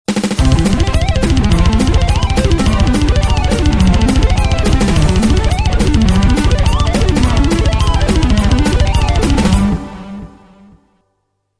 1) sweep :